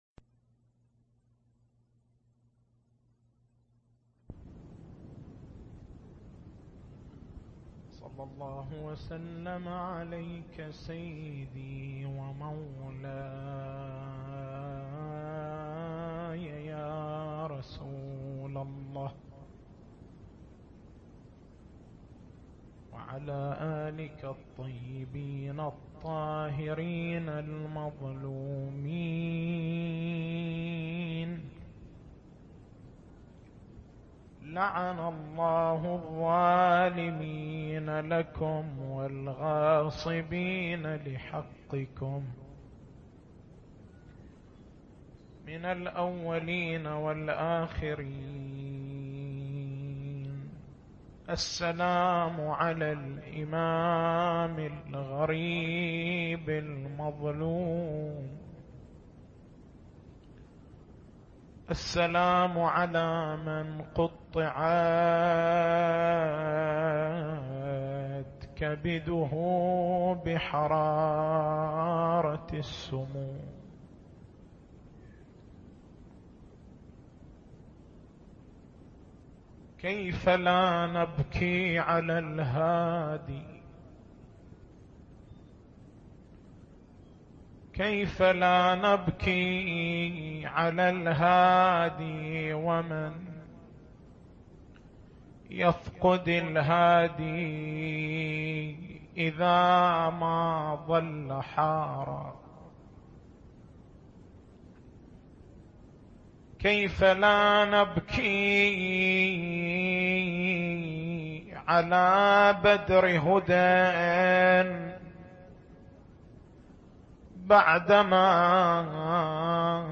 تاريخ المحاضرة: 03/07/1426 التسجيل الصوتي: شبكة الضياء > مكتبة المحاضرات > مناسبات متفرقة > أحزان آل محمّد